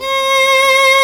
Index of /90_sSampleCDs/Roland - String Master Series/STR_Viola Solo/STR_Vla2 Warm vb